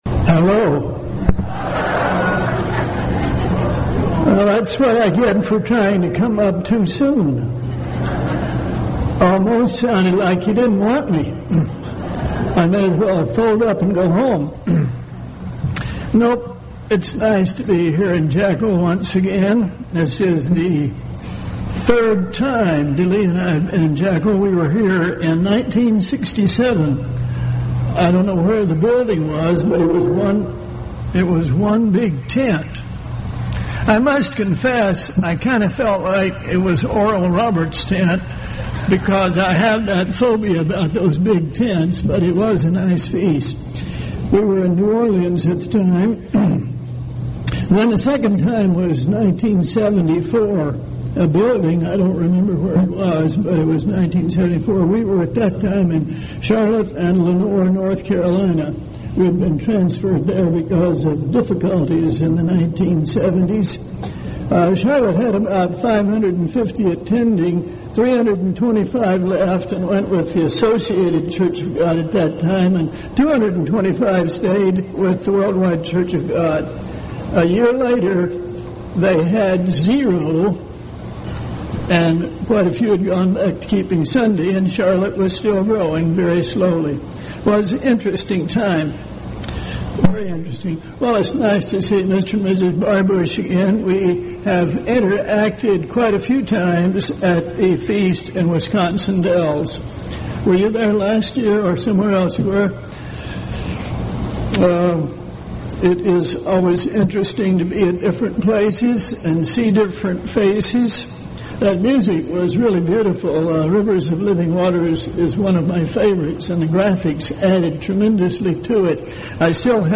This sermon was given at the Jekyll Island, Georgia 2011 Feast site.